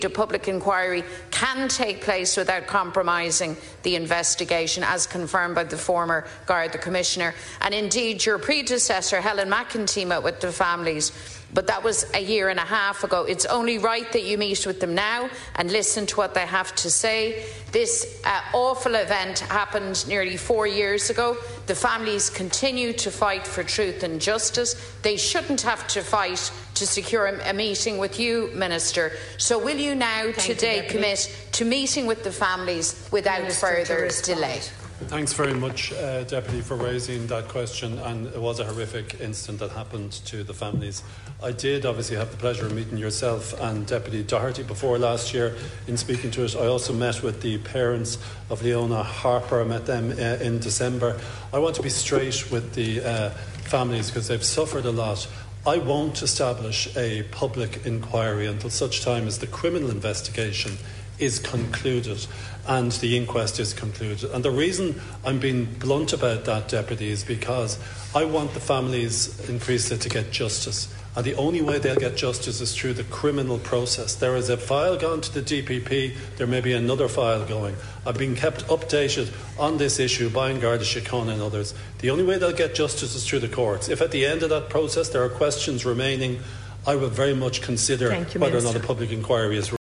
He was responding in the Dáil to Sinn Féin leader Mary Lou McDonald who said that a public inquiry could take place alongside the criminal investigation.
Minister O’Callaghan restates his belief that justice is served through criminal proceedings: